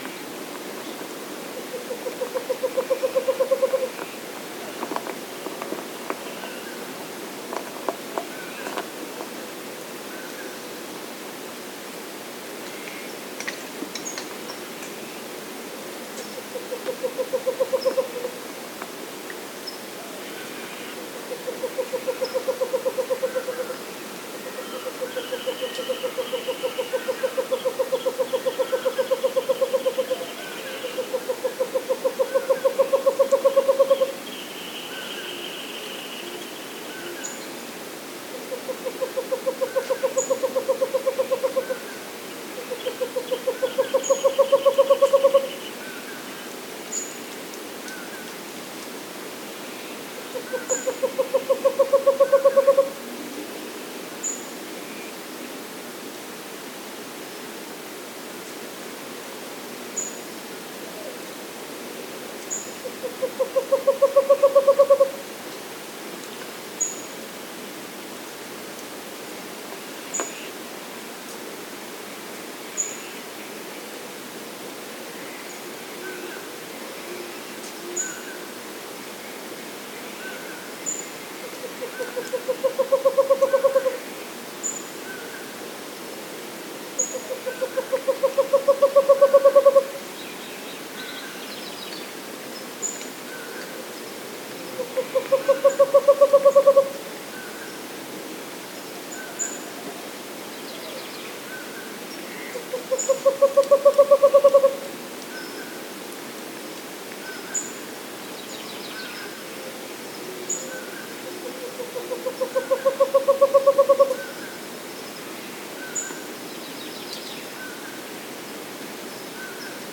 Western Otus kenicottii
pwep pwep pwep whistles, a soft, mournful whinny that aroused fear and suspicion among early settlers.
westernscreech.mp3